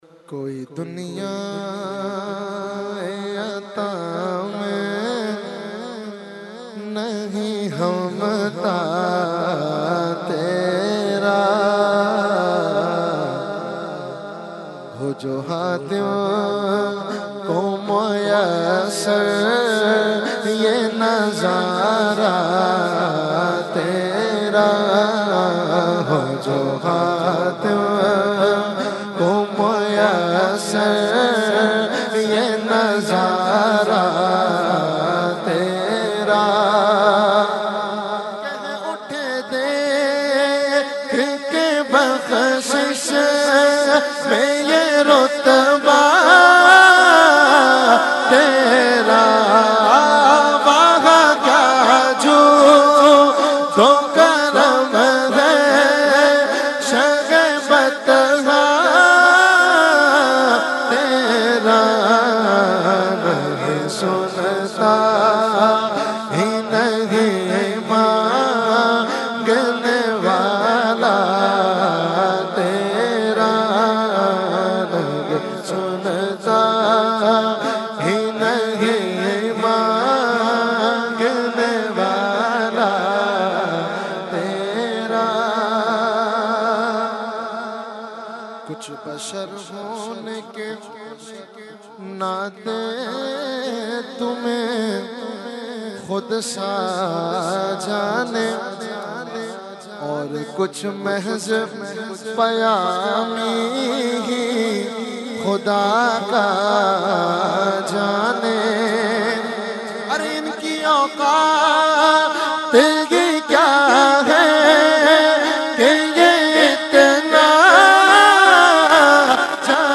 held on 21,22,23 December 2021 at Dargah Alia Ashrafia Ashrafabad Firdous Colony Gulbahar Karachi.
Category : Naat | Language : UrduEvent : Urs Qutbe Rabbani 2021-2